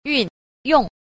3. 運用 – yùnyòng – vận dụng
yun_yong.mp3